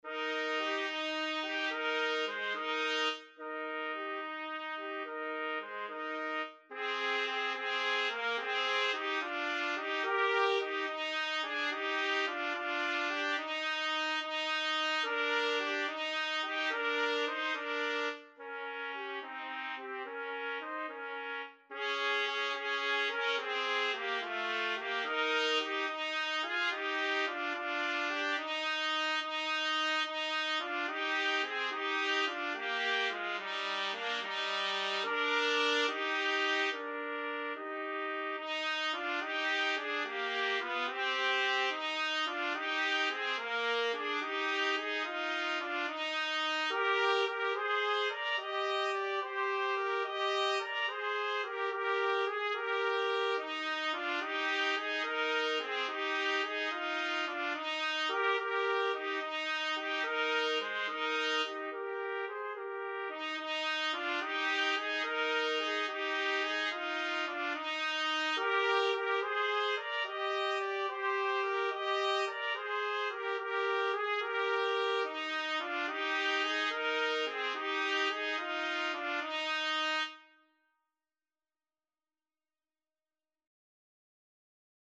6/8 (View more 6/8 Music)
Maestoso . = c. 72
Classical (View more Classical Trumpet Duet Music)